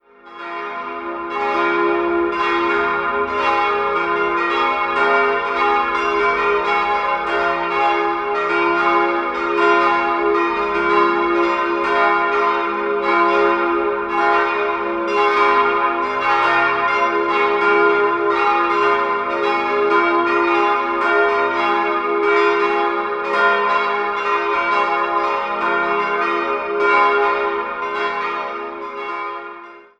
4-stimmiges Geläut: f'-as'-b'-c'' Die Glocken wurden 1957 von der Gießerei Friedrich Wilhelm Schilling in Heidelberg gegossen.